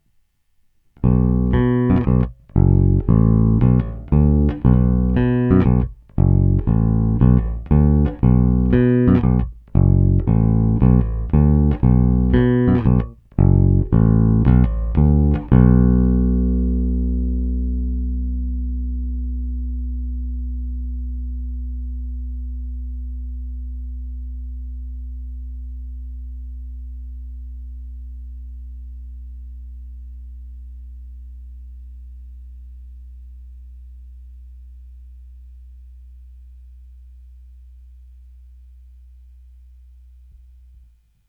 Plné basy, vrčivé středy, zvonivé výšky.
Není-li uvedeno jinak, následující nahrávky jsou nahrány rovnou do zvukovky a dále jen normalizovány. Použité struny jsou niklové roundwound struny D'Addario v tloušťkách .045"-.065"-.085"-.105" ve skoro novém stavu.
Hra nad snímačem